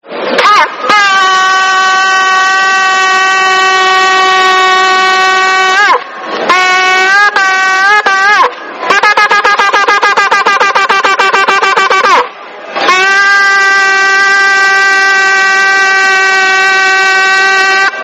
Shofar Acoustics
shofar1.mp3